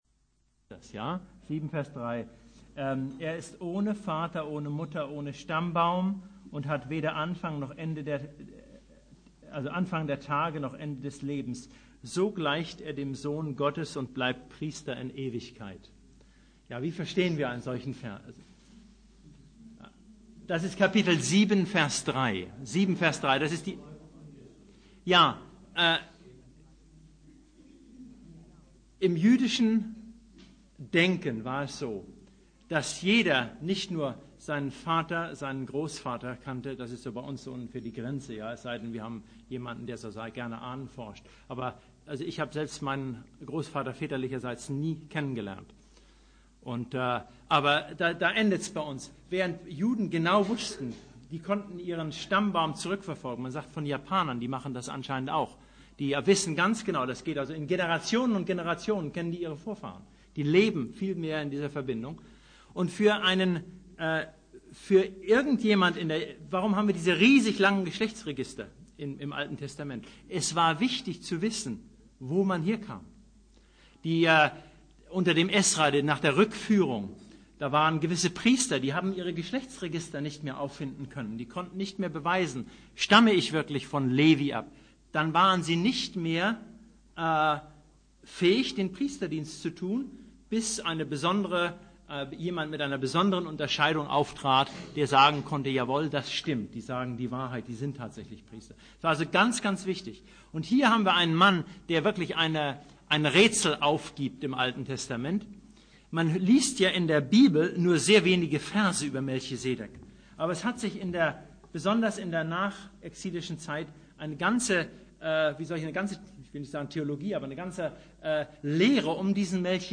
Predigt
Lehrabend: Hebräerbrief III - Kapitel 5,11-6,12; 10,19-31; 12,12-17 - Keine Möglichkeit zur Buße?